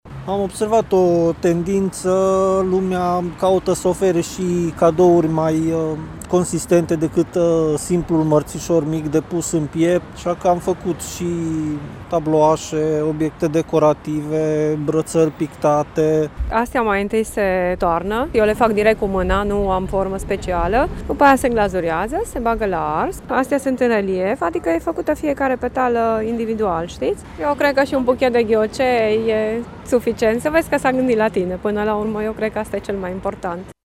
Care este tendința din acest an ne spun artizanii: